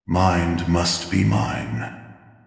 This product is a self-made supporting voice for my heart control hero infantry unit "Cain"
This voice set comes with reverberation echo effect, and the voice content is related to the attack type mind control.